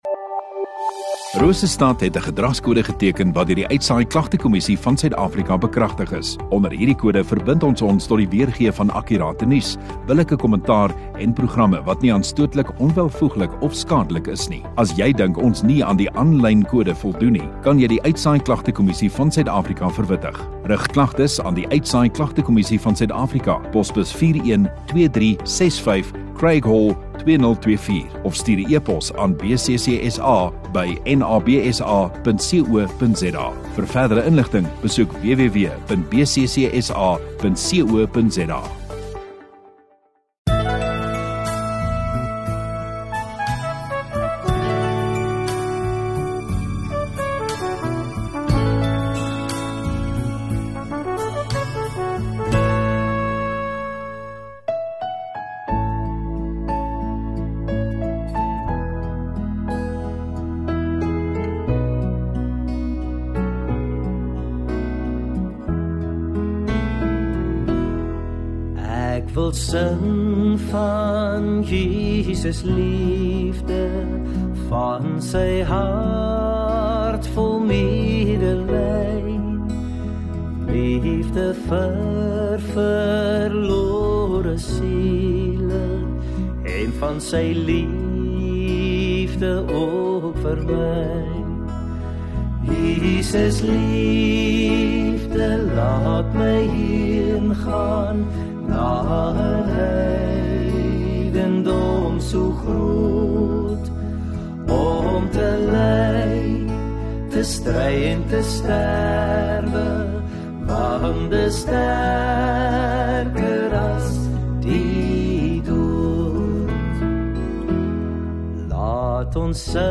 20 Sep Saterdag Oggenddiens